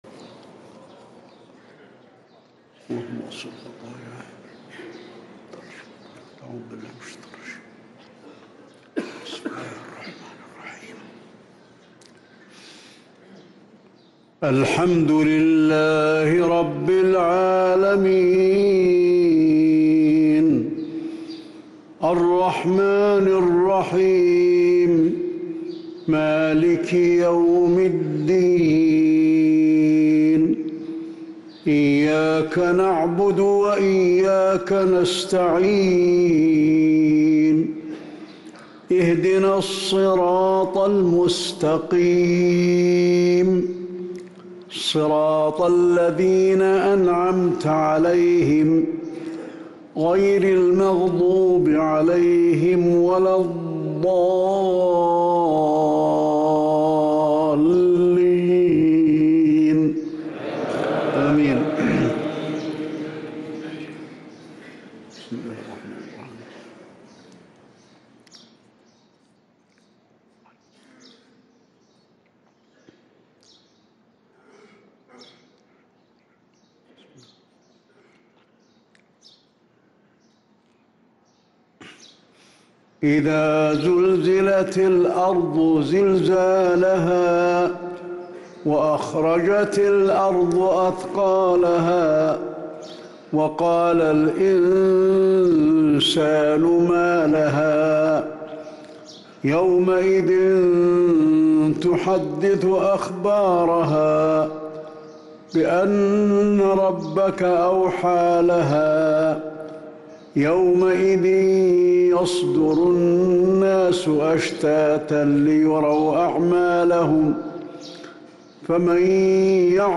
صلاة العشاء للقارئ حسين آل الشيخ 3 رمضان 1444 هـ